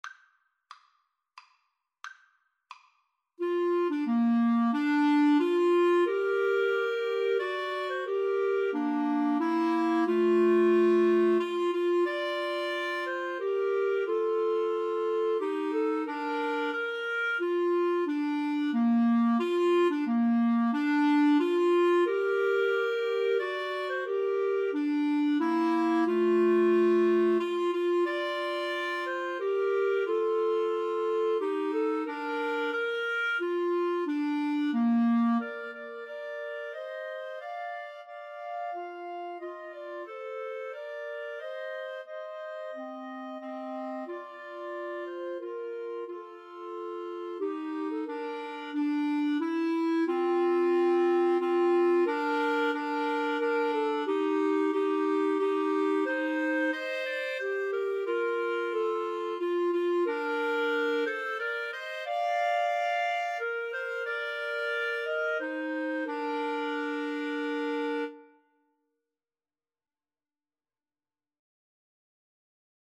Bb major (Sounding Pitch) C major (Clarinet in Bb) (View more Bb major Music for Clarinet Trio )
Maestoso = c.90
3/4 (View more 3/4 Music)
Clarinet Trio  (View more Intermediate Clarinet Trio Music)